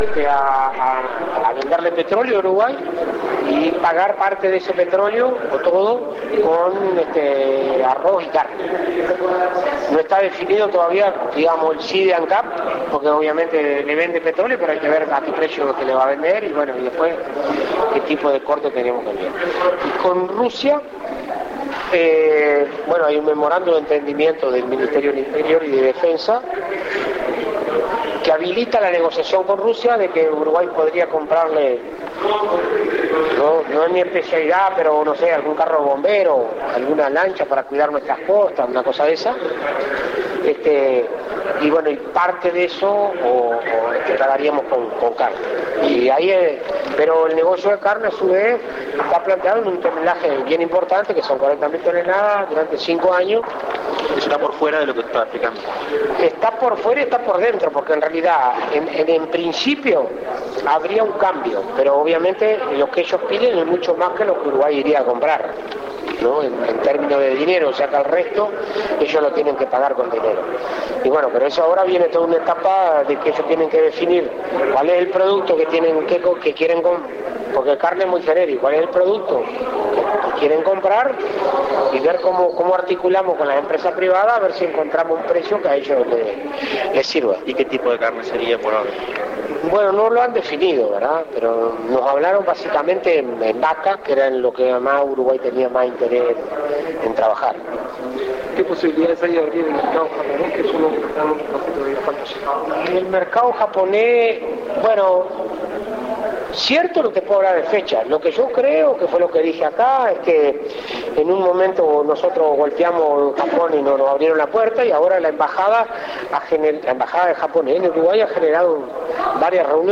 nota periodística Fratti. mp3. 6:30